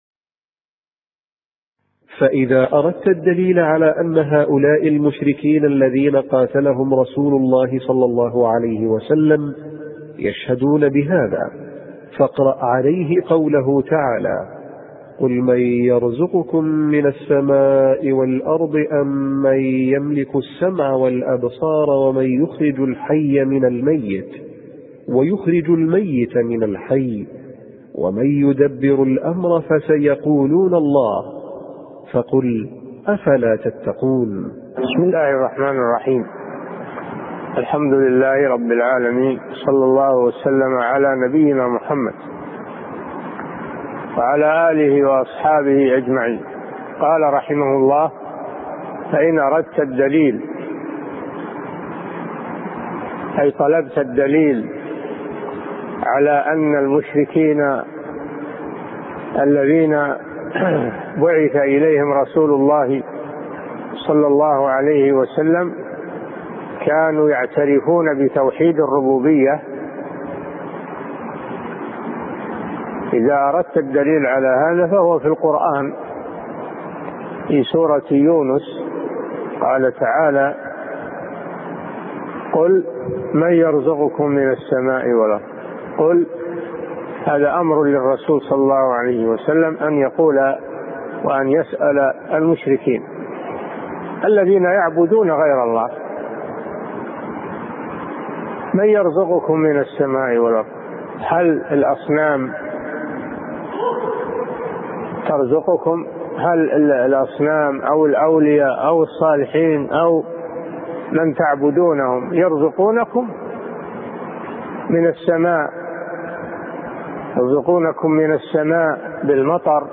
أرشيف الإسلام - ~ أرشيف صوتي لدروس وخطب ومحاضرات الشيخ صالح بن فوزان الفوزان